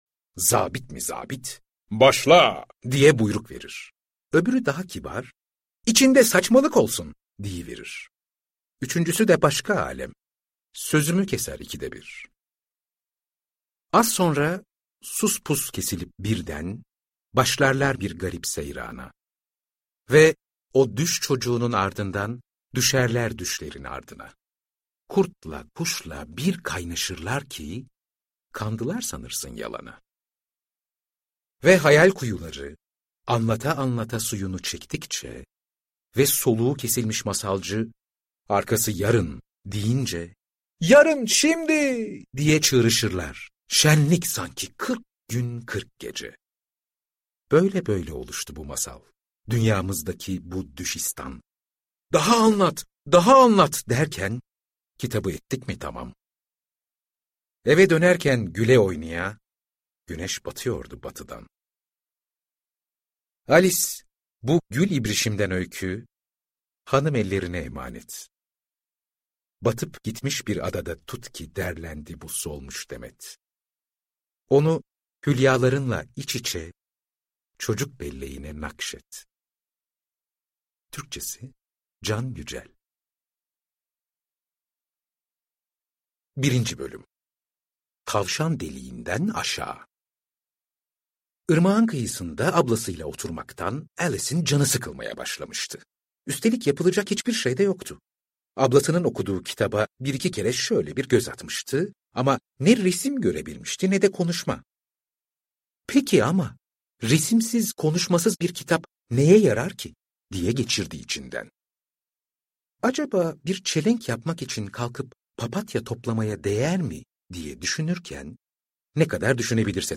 Alice Harikalar Ülkesinde - Seslenen Kitap